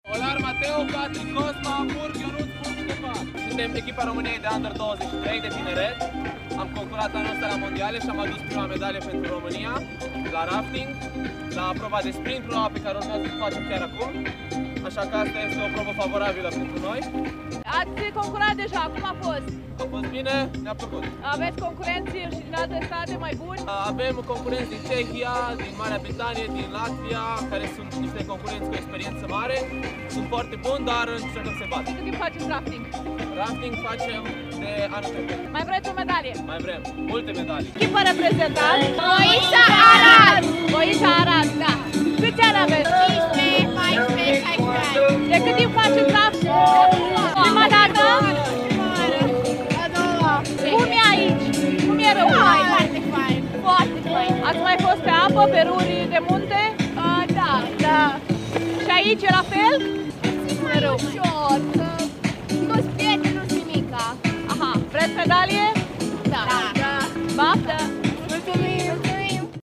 Rafting-voxuri-romania.mp3